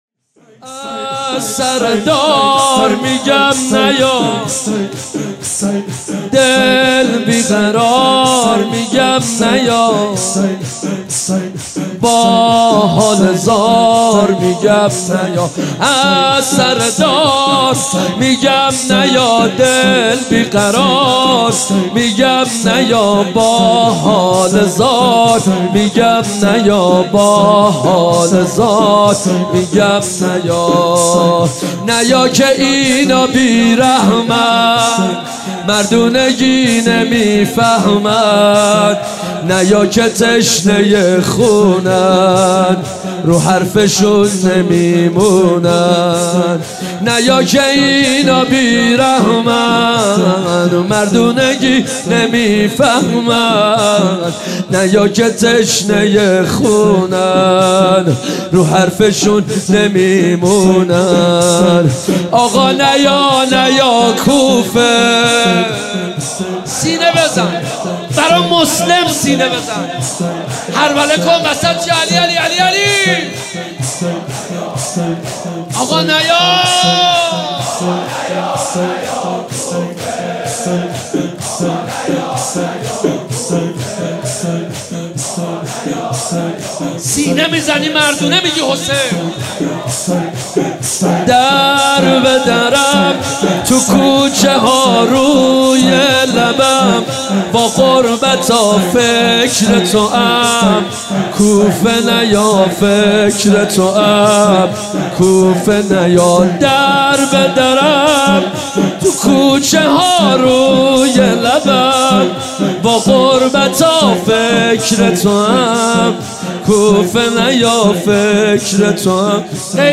شب اول محرم الحرام 1394 | هیات مکتب الحسین اصفهان
از سر دار میگم نیا | شور | حضرت مسلم بن عقیل علیه السلام